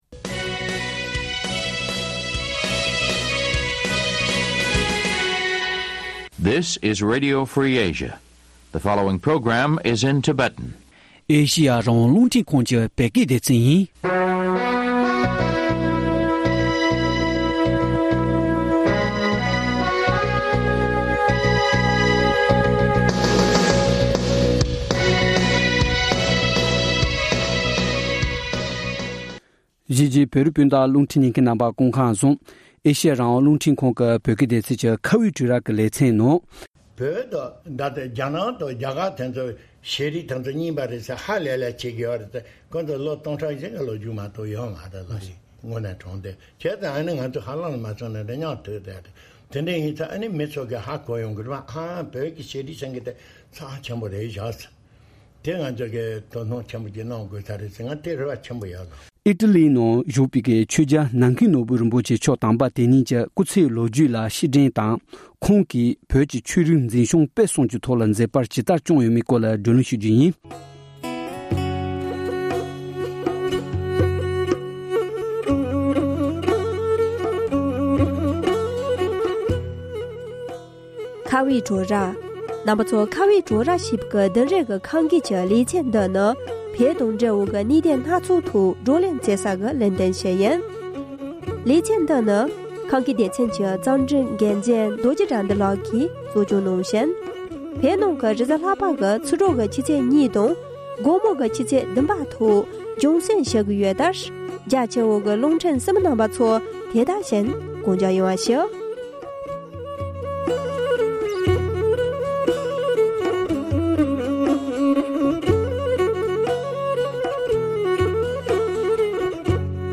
ཁོང་གིས་བོད་ཀྱི་ཆོས་རིག་འཛིན་སྐྱོང་སྤེལ་གསུམ་ཐོག་མཛད་པ་ཇི་ལྟ་བསྐྱངས་ཡོད་པའི་སྐོར་གྱི་བགྲོ་གླེང་།